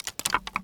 wood_tree_branch_move_07.wav